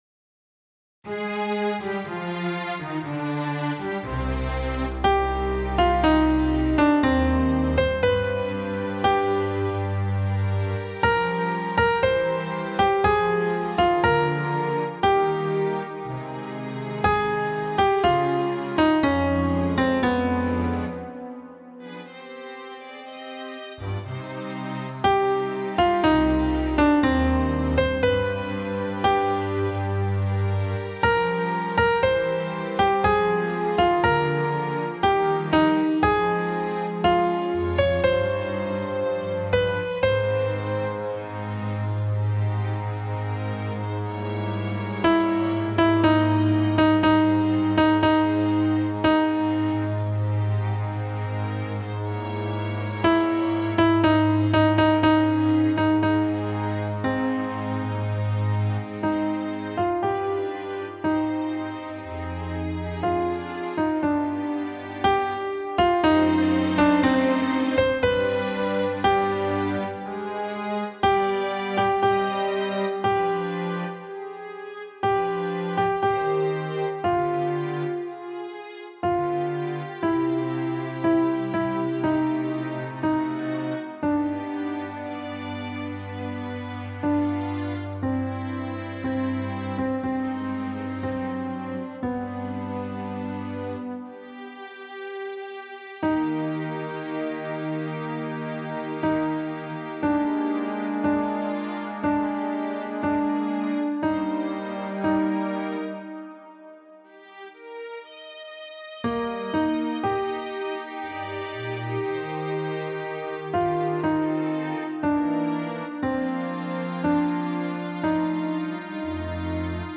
Contralto
Mp3 Música